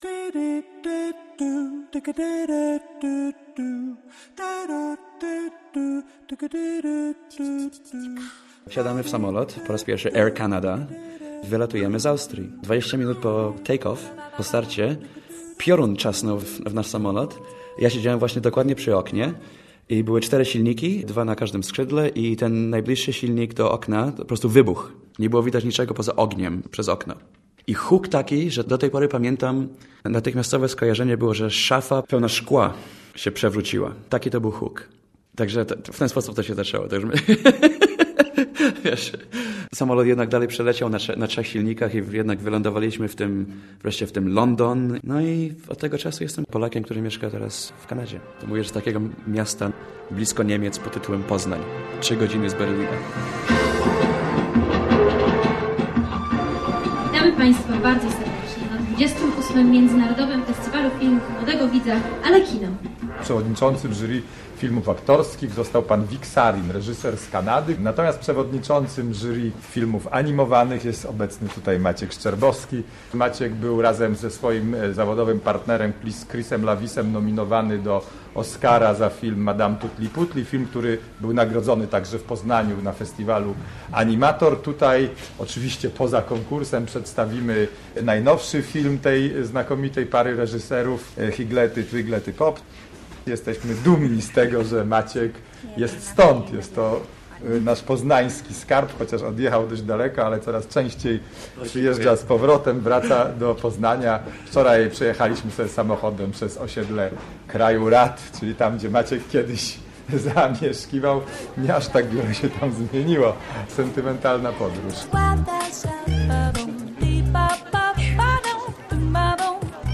Życie to musi być coś więcej - reportaż